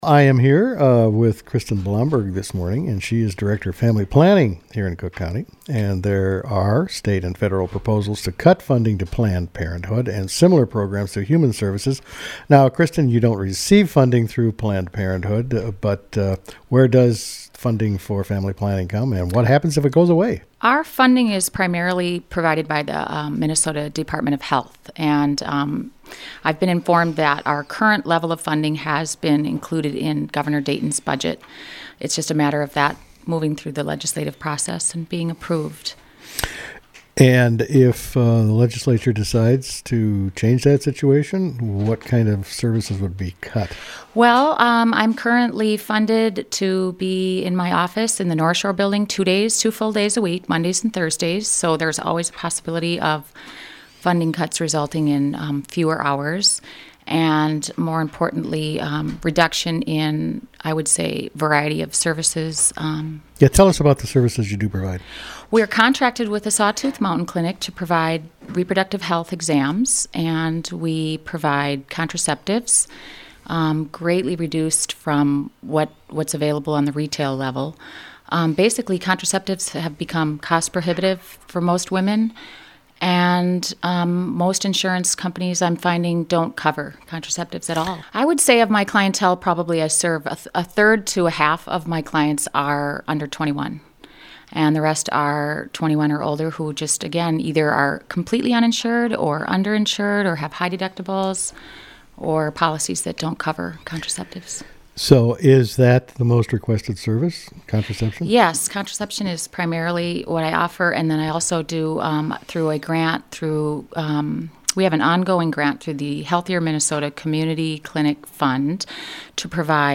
The complete interview is also included.